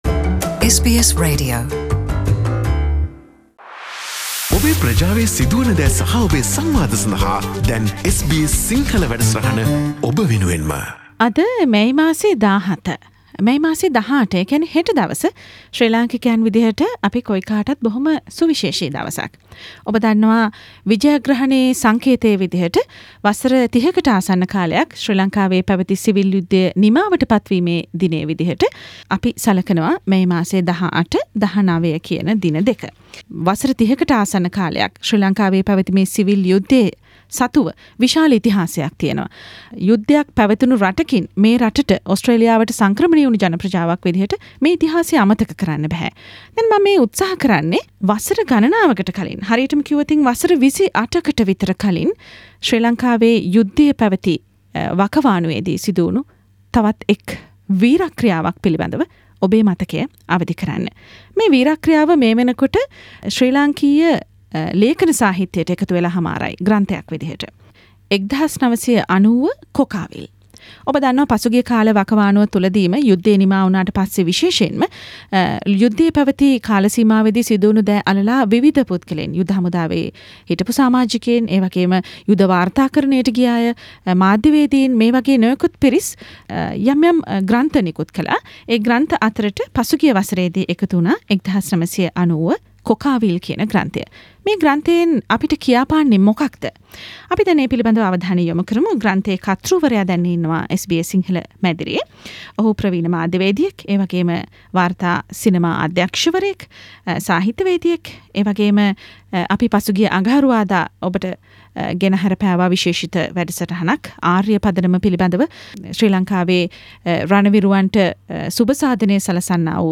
සම්මුඛ සාකච්චාවෙන්